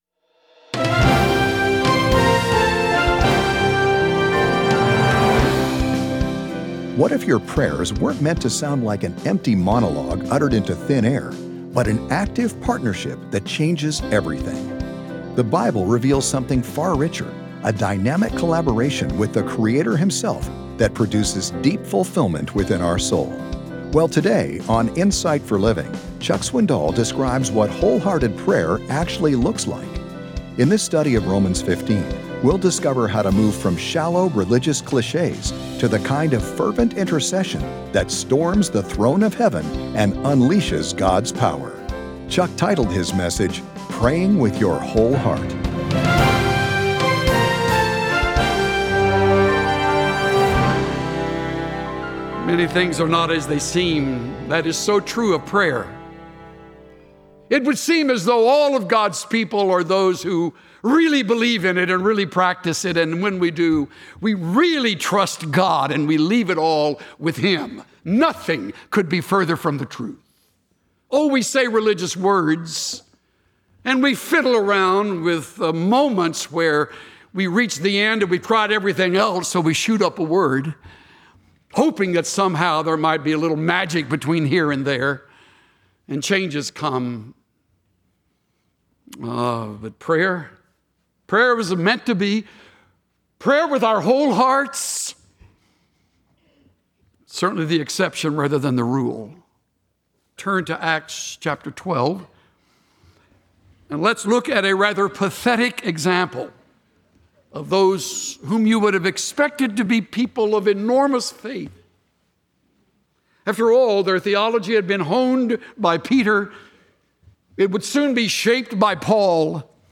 Tune in to hear Pastor Chuck Swindoll discuss four principles for effective prayer from Romans 15:30–33.